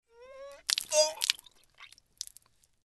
Подборка создана для проектов, где нужна мрачная или тревожная атмосфера.
Жидкость вытекает изо рта